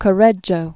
(kə-rĕjō, kō-rĕdjō), Antonio Allegri da 1494?-1534.